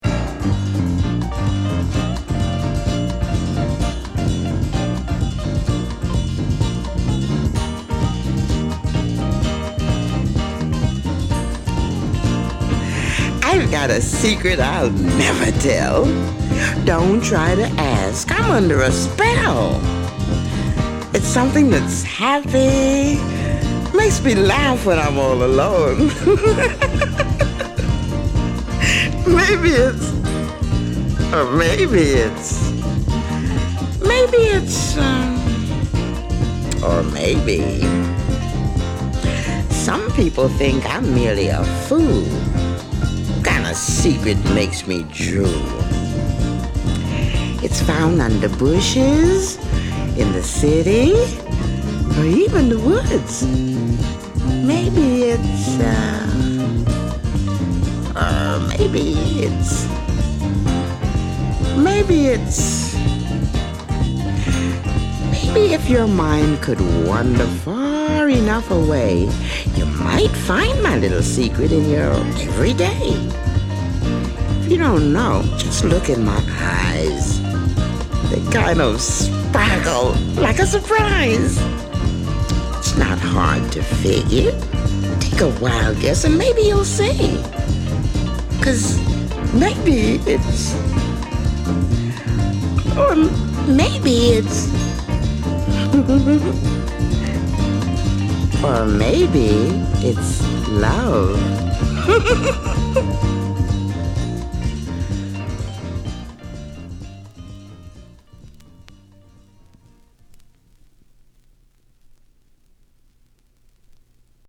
I like this song cause it's rather frantic and crazy!